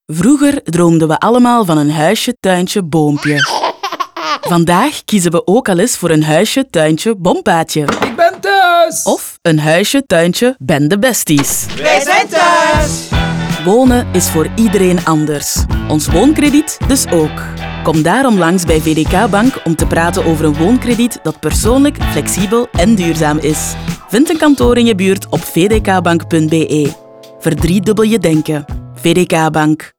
vdk Bank-Woonkrediet-radio-nl-30s.wav